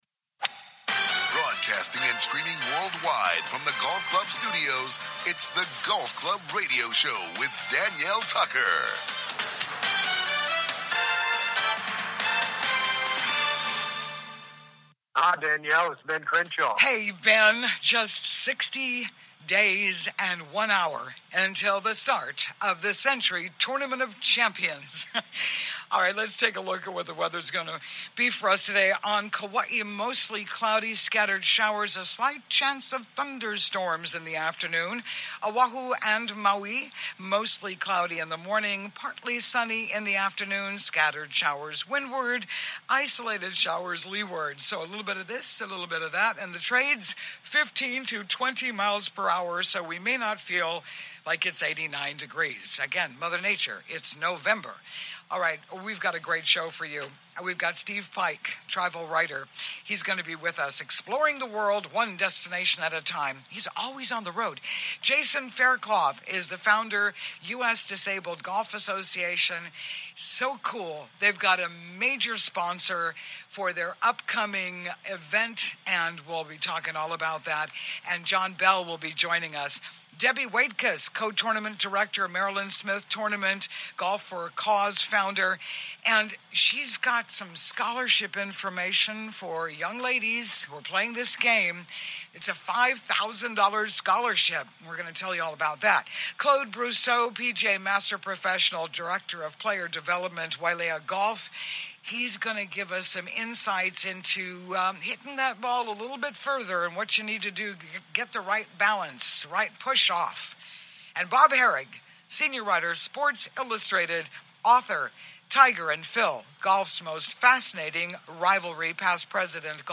Headliner Embed Embed code See more options Share Facebook X Subscribe In The Clubhouse: COMING TO YOU LIVE FROM THE GOLF CLUB STUDIOS ON LOVELY OAHU’s SOUTH SHORE WELCOME INTO THE GOLF CLUB HOUSE!